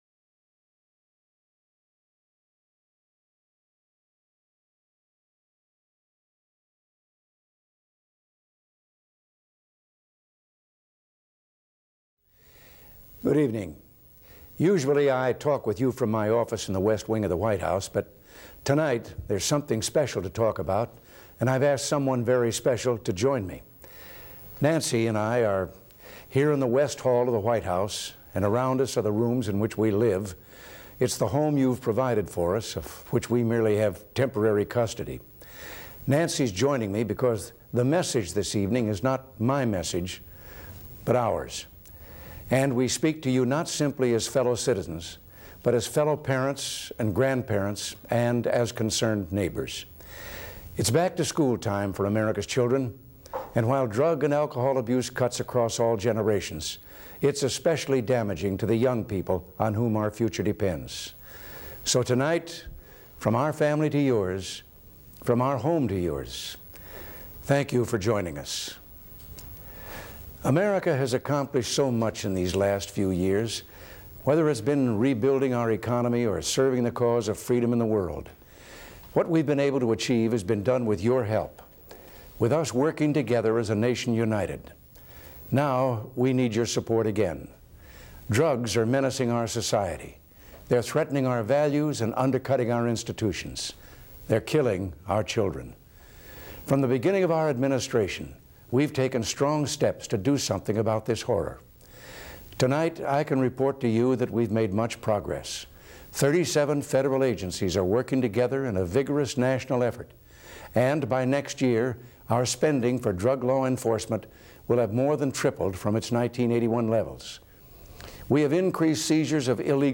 September 14, 1986: Speech to the Nation on the Campaign Against Drug Abuse